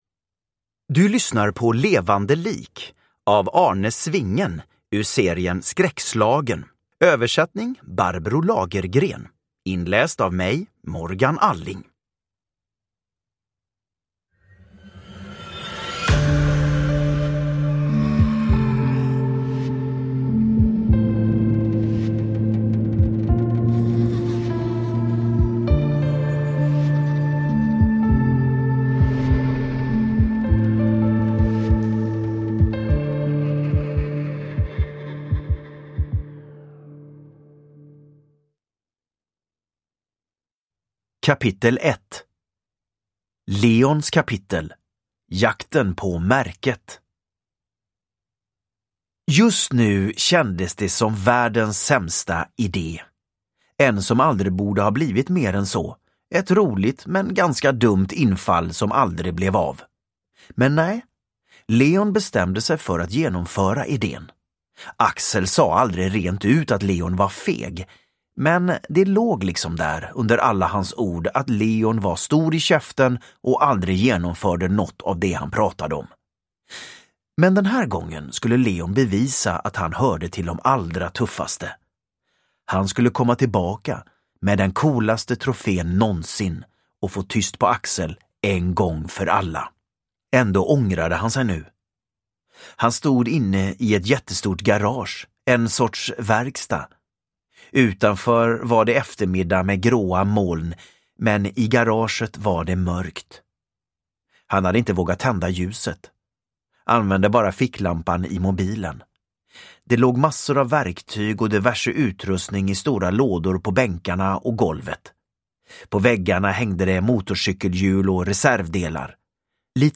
Levande lik – Ljudbok – Laddas ner
Uppläsare: Morgan Alling